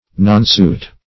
nonsuit - definition of nonsuit - synonyms, pronunciation, spelling from Free Dictionary
Nonsuit \Non"suit`\, n. (Law)